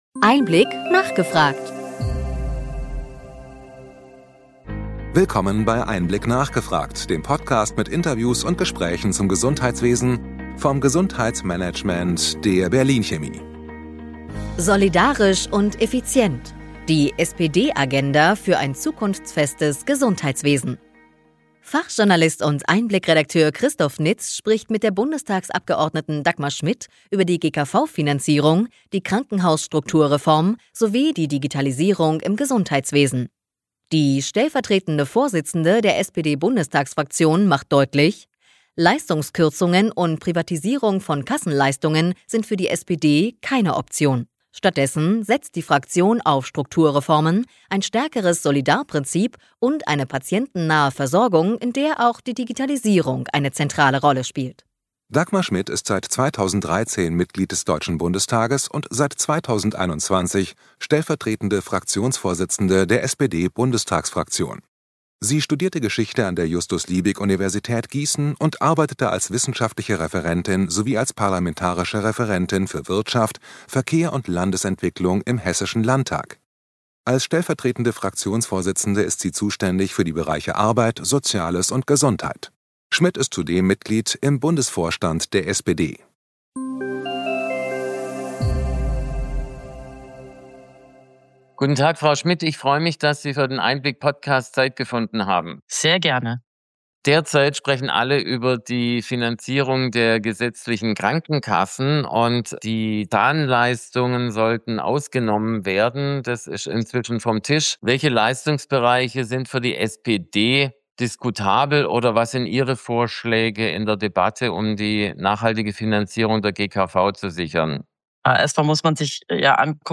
EinBlick – nachgefragt Podcast mit Interviews und Diskussionsrunden mit Expert:innen des Gesundheitswesens